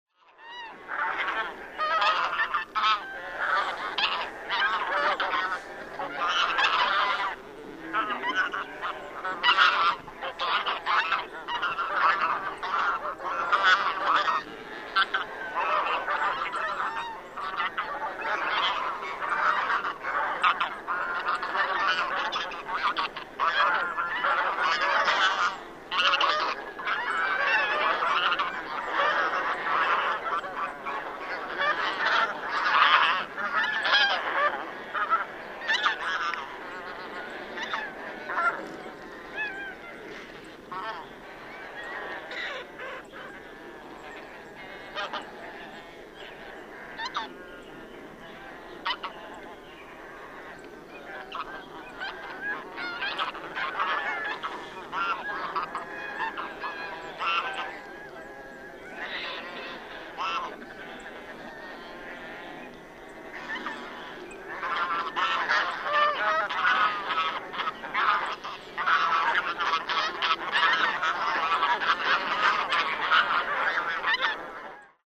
Tundra Bean Goose